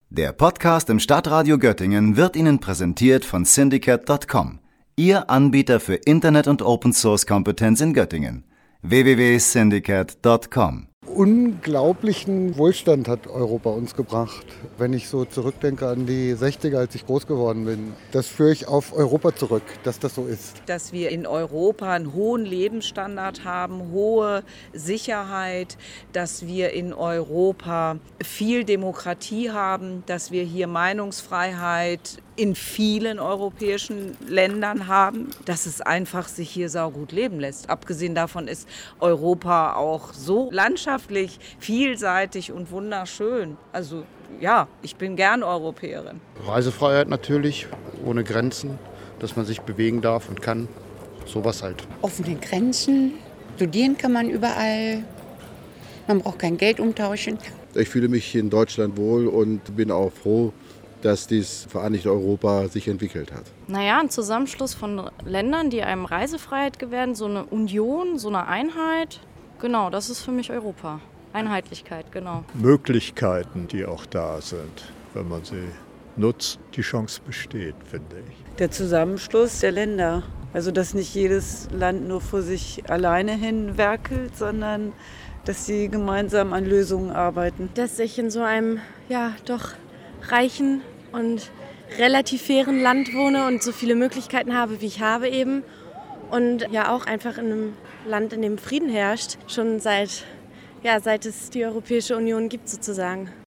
hat sich in der Innenstadt umgehört.